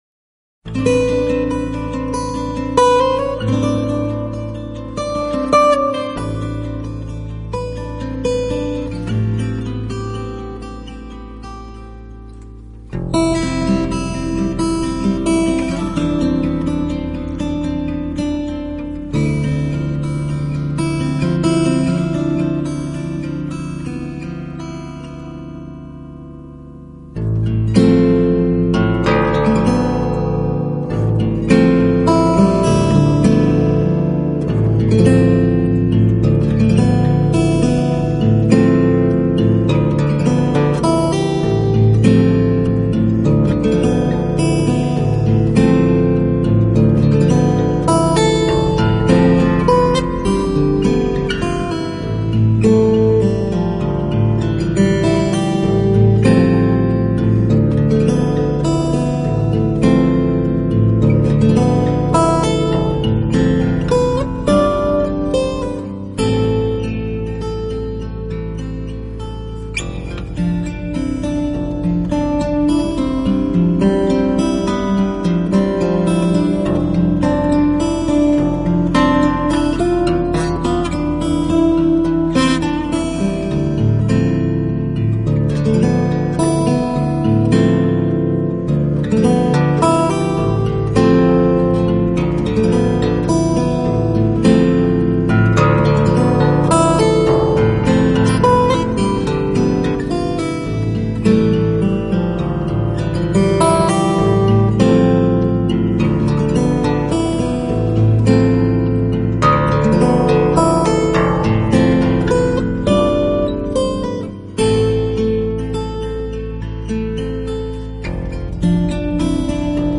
【指弹吉他】
快速流